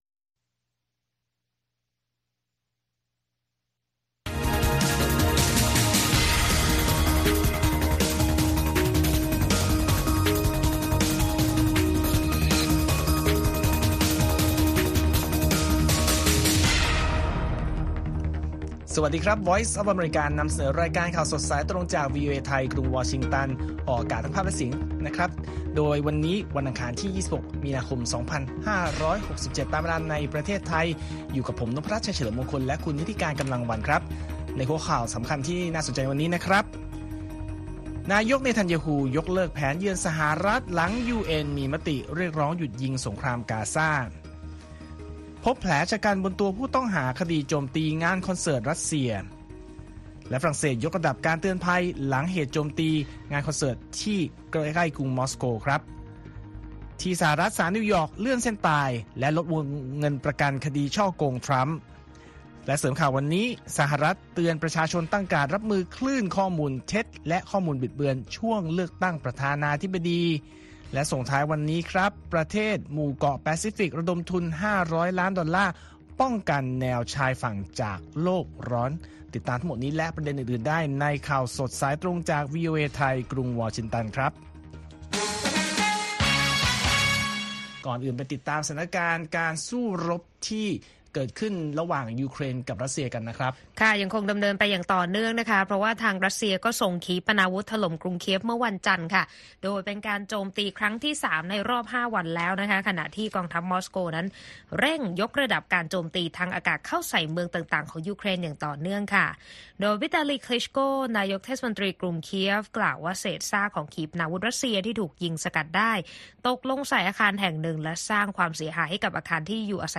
ข่าวสดสายตรงจากวีโอเอไทย อังคาร ที่ 26 มี.ค. 67